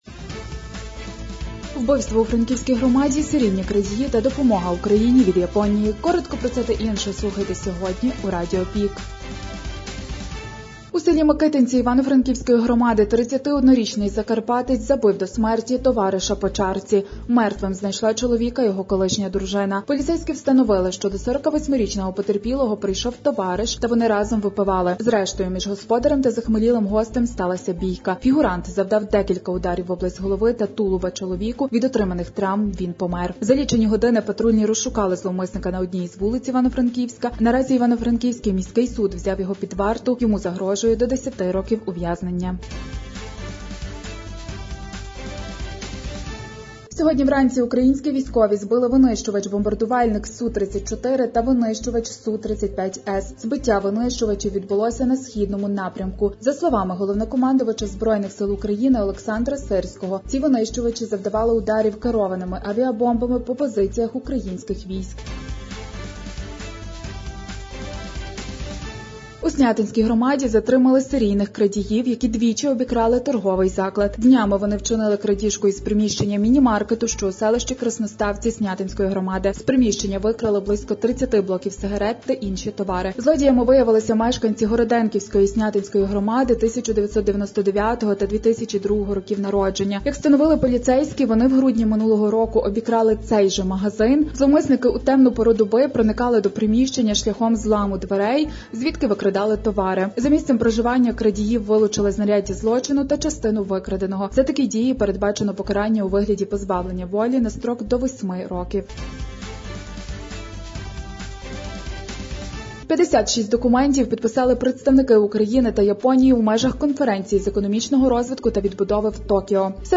Актуальне за день у радіоформаті.